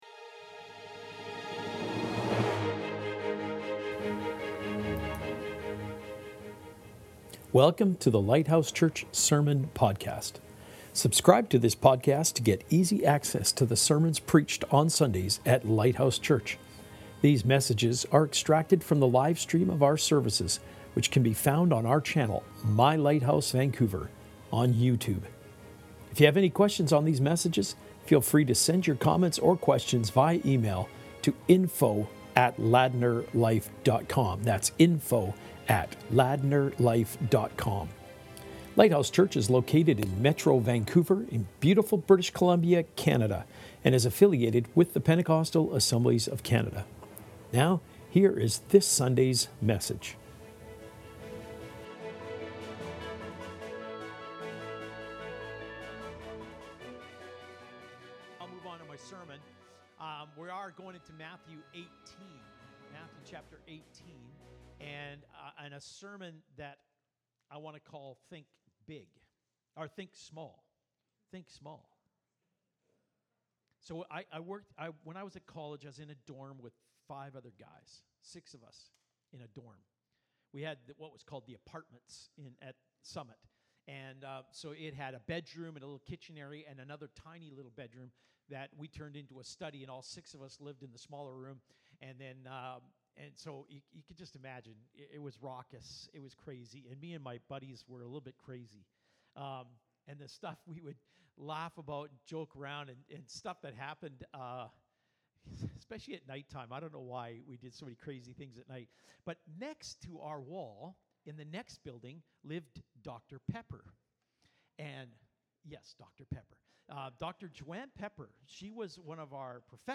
Sermons | Lighthouse Church